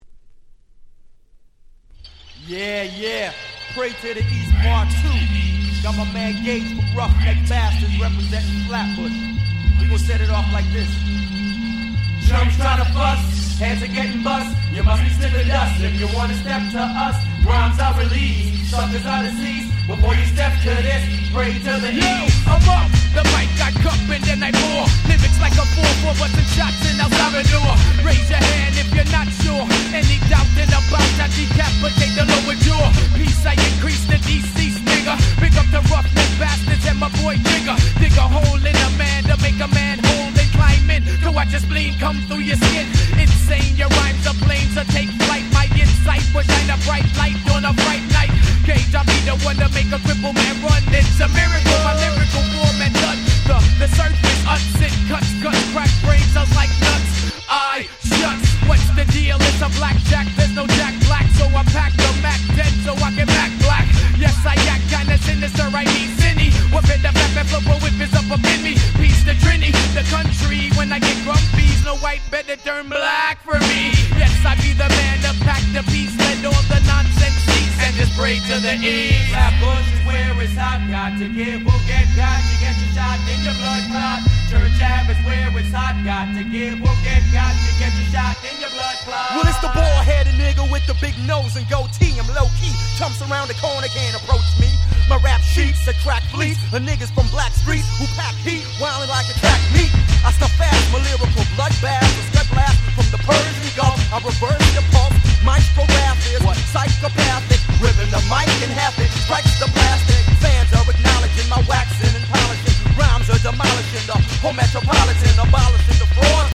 Canadian Hip Hop最高峰！！